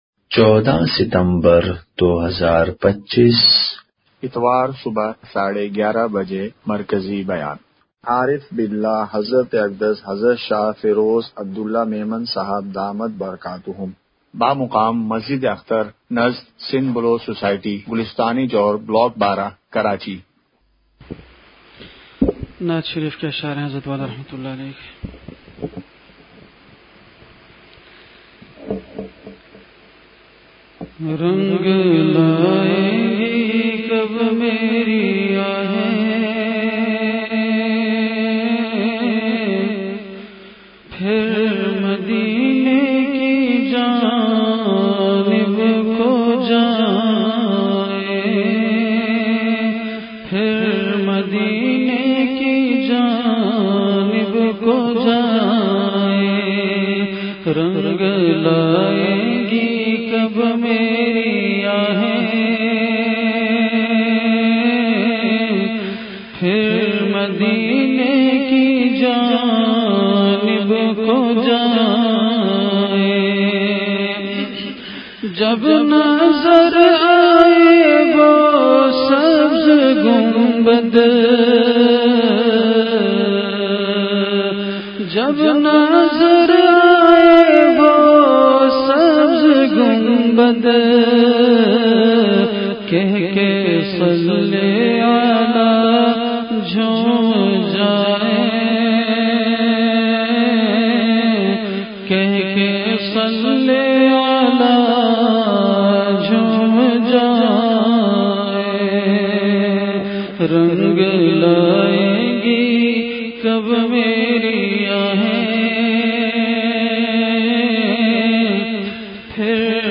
*مقام:مسجد اختر نزد سندھ بلوچ سوسائٹی گلستانِ جوہر کراچی*
21:15) بیان سے قبل اشعار اور تعلیم ہوئی۔۔۔